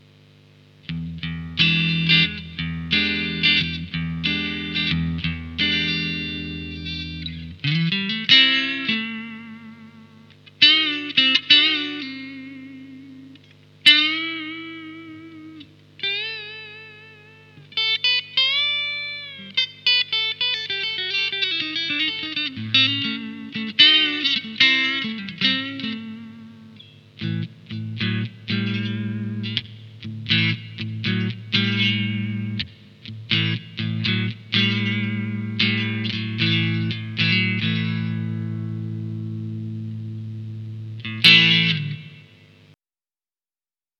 Bon allez, pour terminer en beauté, voici les samples en clean.
le dernier sonne synthétique!
Le preamp 4 est le PSA-1
En sons clairs, la différence est plus minime entre les preamp, sauf le PSA-1 qui n'a pas de reglage de mediums et donne donc un son plus creusé ...
ComparatifPreamp-Clean-Preamp4.mp3